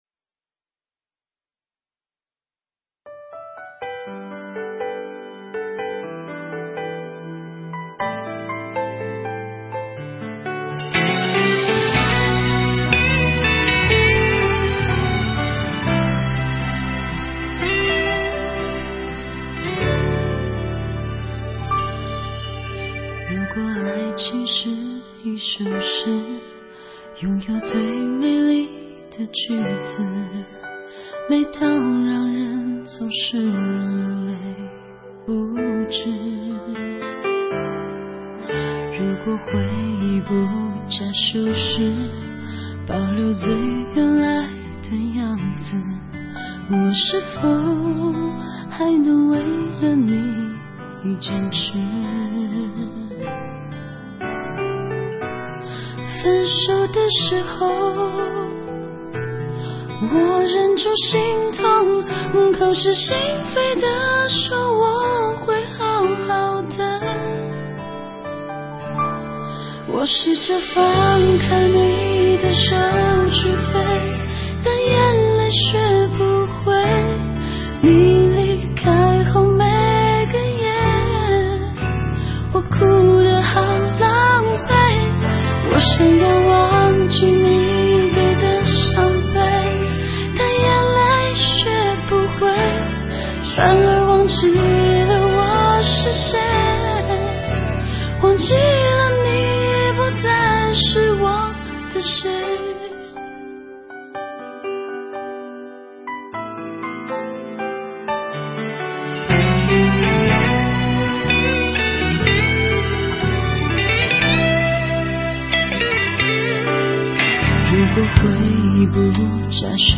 钢琴弹奏的音符，性感磁性的女声，像极了歌手丁当的声音和风格，这是正宗伤情歌啊！